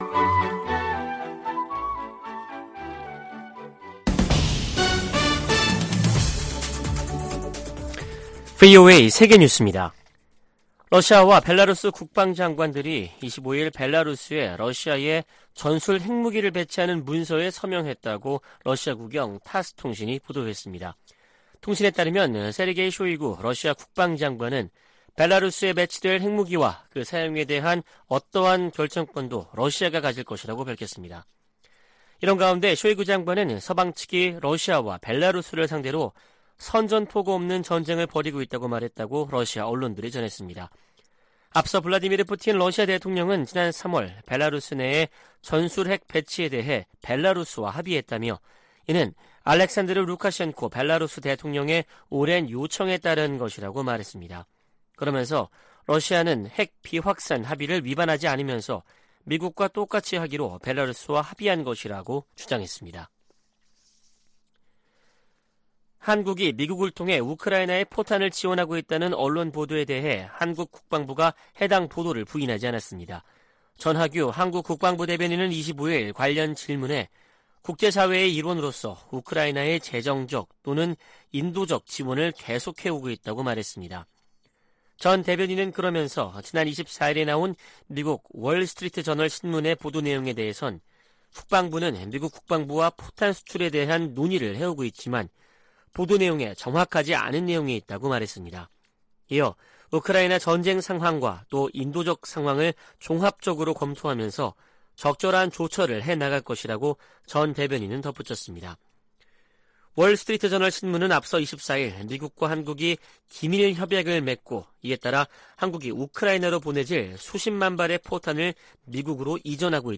VOA 한국어 아침 뉴스 프로그램 '워싱턴 뉴스 광장' 2023년 5월 26일 방송입니다. 조 바이든 미국 대통령이 최근 미한일 정상회담에서 한일 정상의 관계 개선 노력을 높이 평가했다고 백악관 고위 관리가 밝혔습니다. 미군과 한국군이 25일부터 한반도 휴전선 인근 지역에서 역대 최대 규모 화력격멸훈련에 돌입했습니다. 북한 등 적국들이 미국 첨단 기술 획득을 시도하고 있다고 미 국무부 차관보가 밝혔습니다.